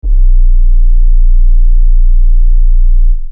808s
Dreiip SUB.wav